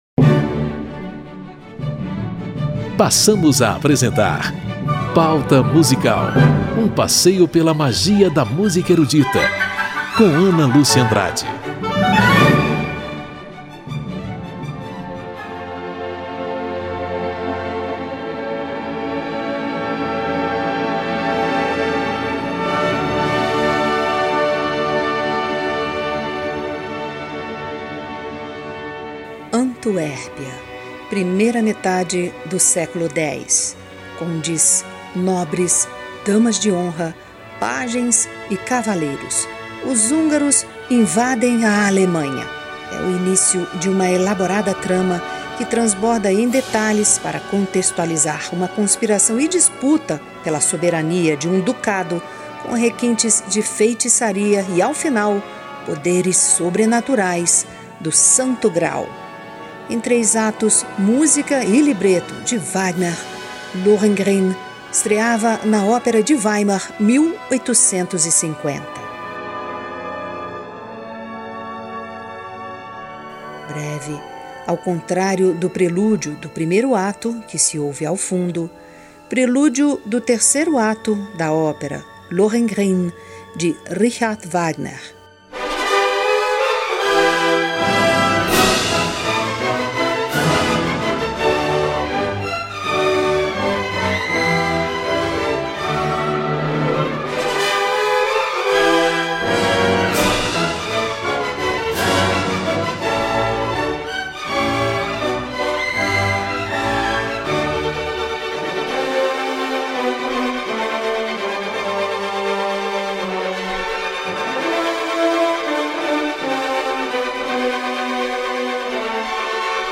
Aberturas, prelúdios e interlúdios de famosas óperas e operetas em série especial de 3 edições. Nesta primeira parte: Lohengrin (Richard Wagner), O Morcego (Johann Strauss II), A Pega Ladra (Gioacchino Rossini), O Franco Atirador (Carl Maria von Weber) e Orfeu no Inferno (Jacques Offenbach). Orquestra Filarmônica de Berlim, regida pelos maestros Klaus Tennstedt e Herbert von Karajan.